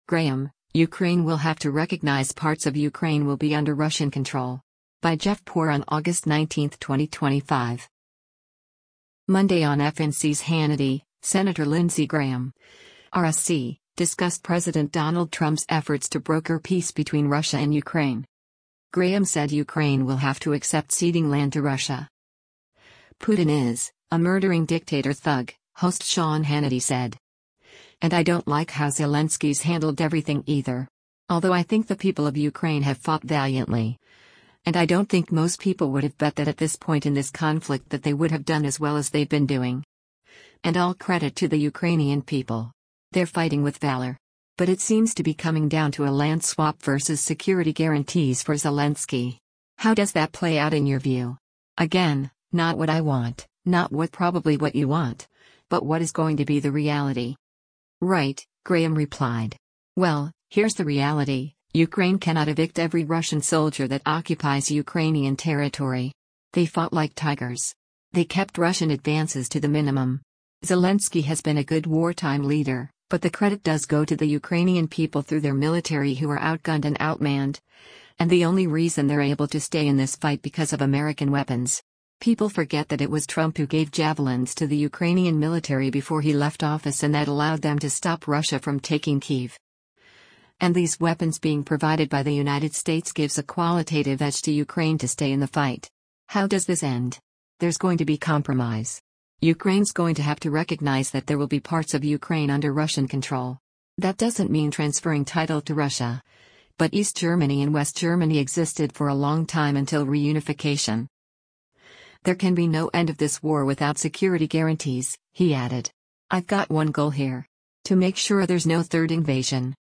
Monday on FNC’s “Hannity,” Sen. Lindsey Graham (R-SC) discussed President Donald Trump’s efforts to broker peace between Russia and Ukraine.